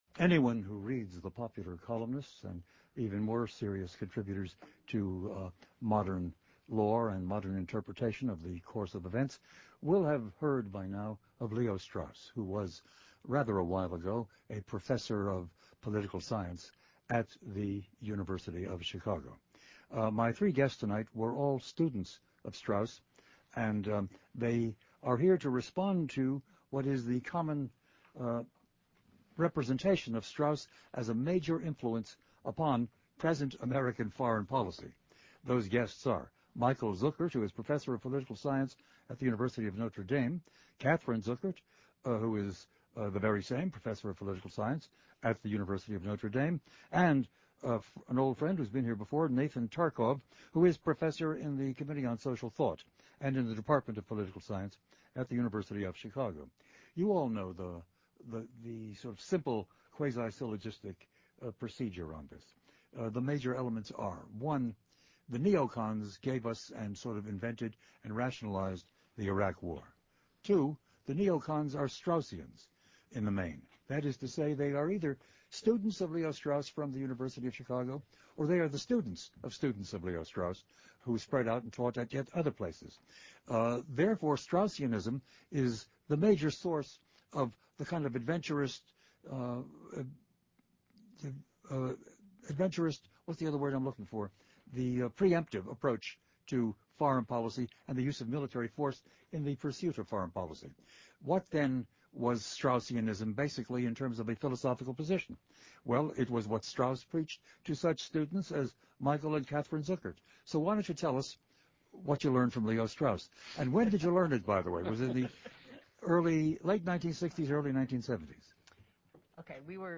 provocative and thought provoking discussion centered on the world of ideas